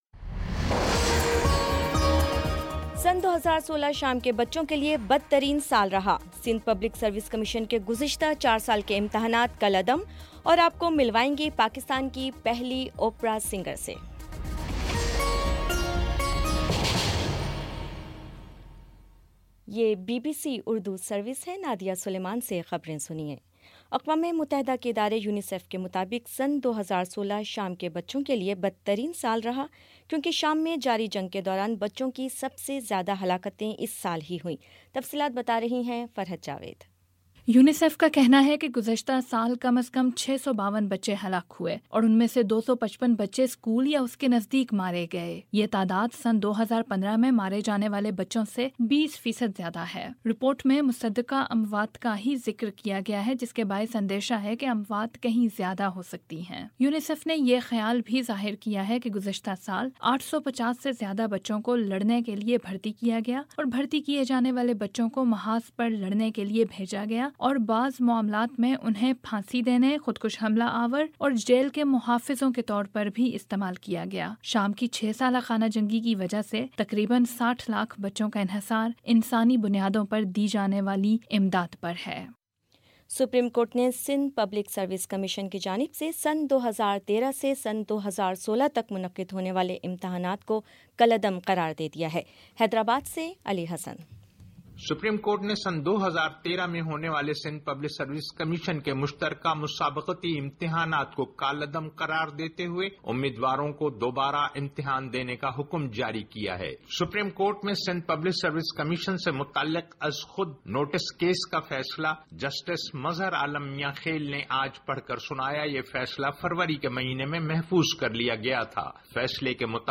مارچ 13 : شام پانچ بجے کا نیوز بُلیٹن